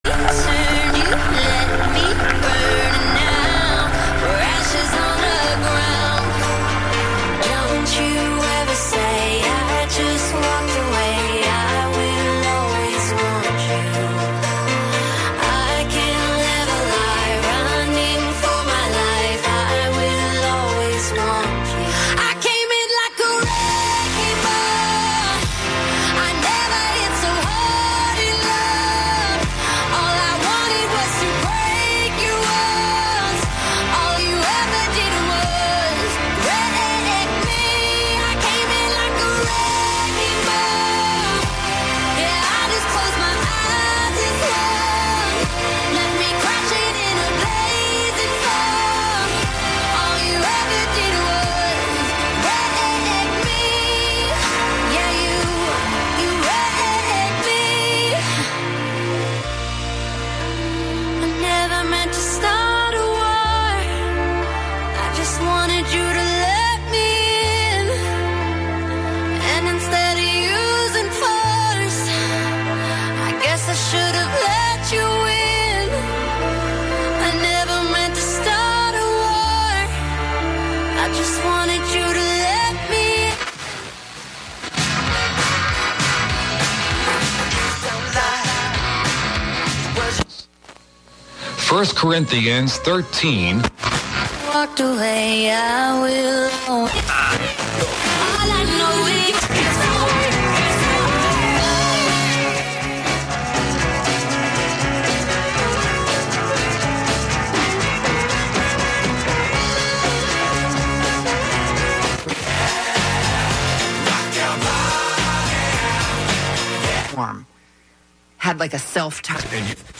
11am Live from Brooklyn, New York
making instant techno 90% of the time